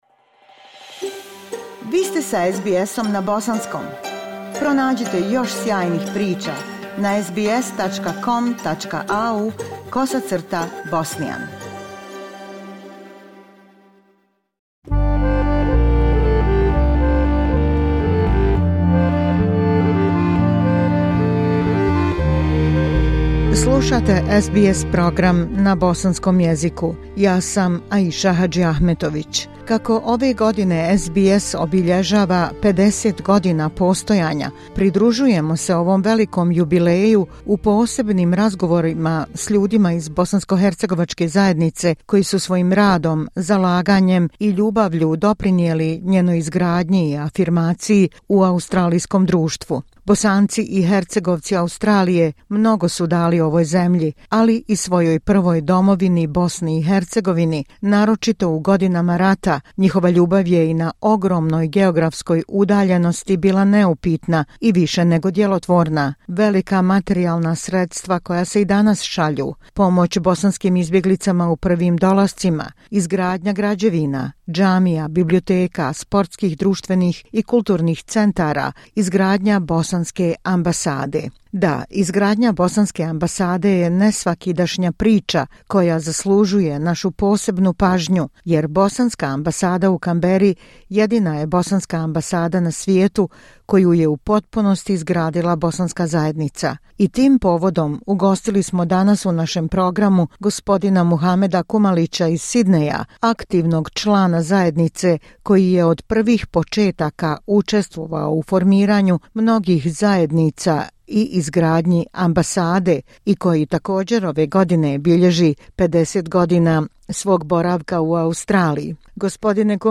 Ove godine SBS obilježava 50 godina postojanja, i mi se pridružujemo ovom velikom jubileju u posebnim razgovorima s ljudima iz bosanskohercegovačke zajednice, koji su svojim radom, zalaganjem i ljubavlju doprinijeli njenoj izgradnji i afirmaciji u australijskom društvu.